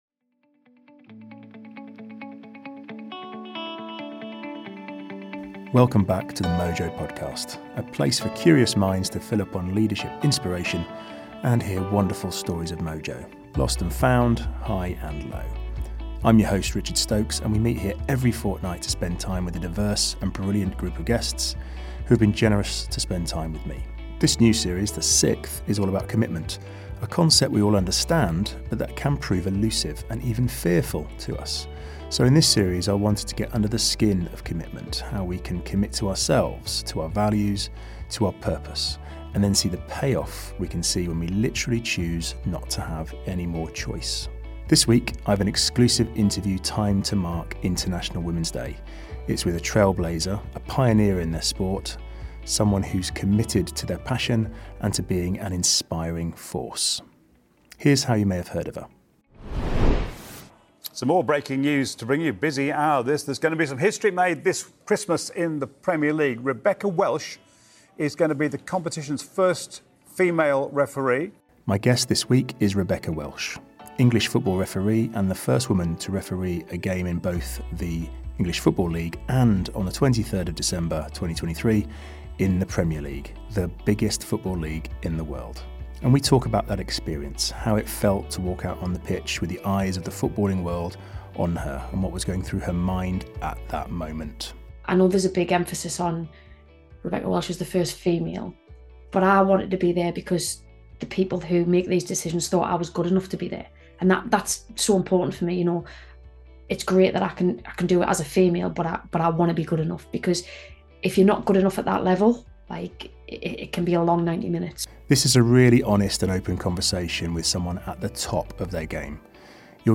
In episode 3 I talk to Rebecca Welch - a genuine trailblazer in her field.